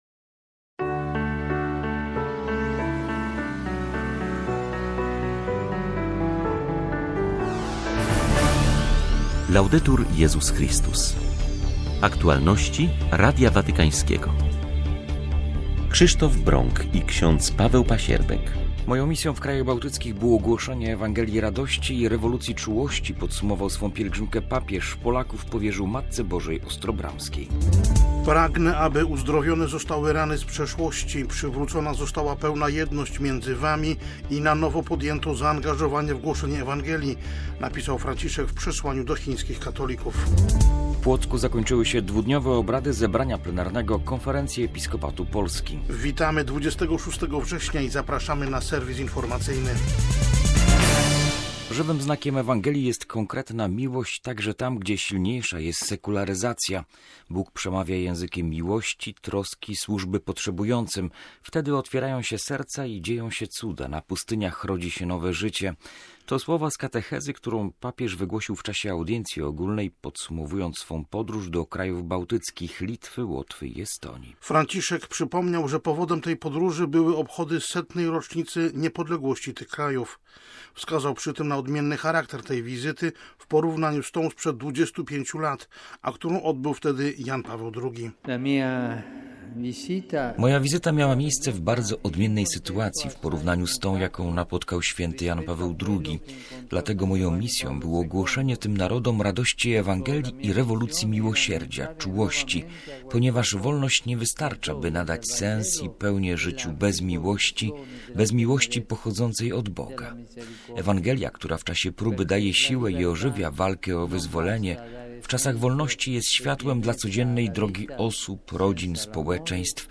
serwis informacyjny